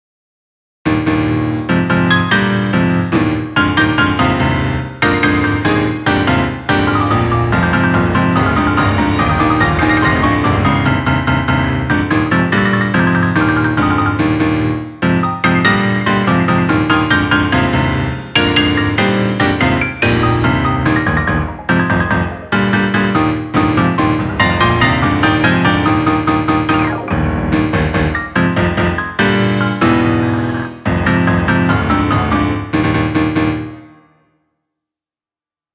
P-inst